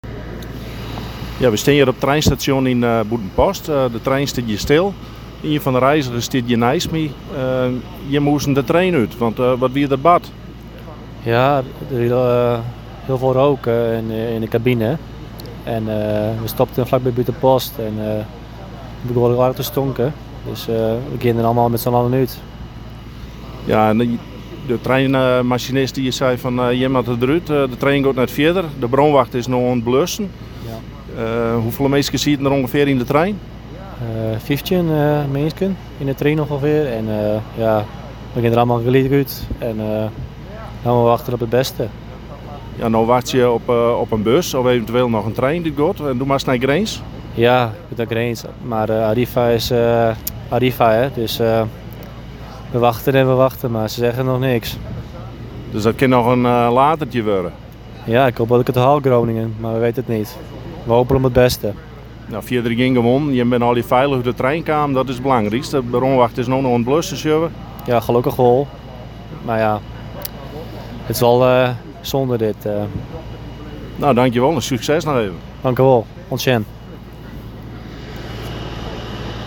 Een gestrande reiziger bij Buitenpost die onderweg was naar Groningen